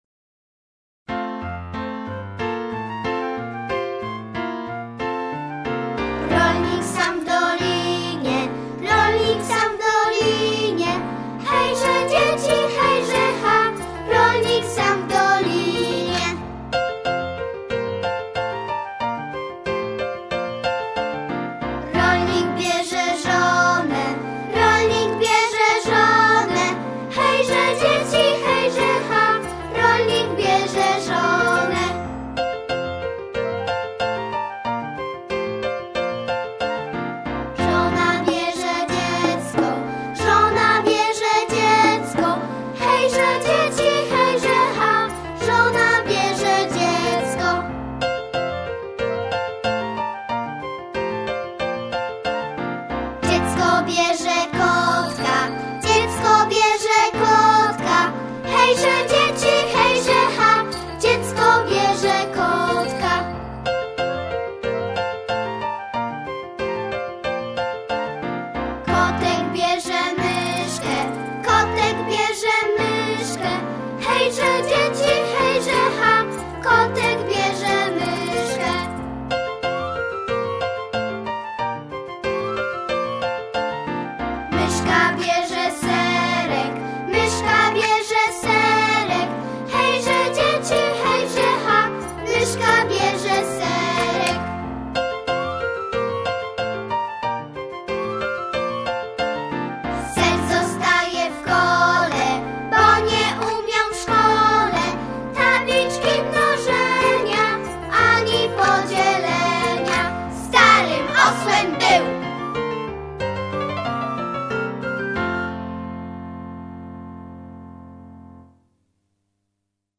Gatunek: Dla dzieci.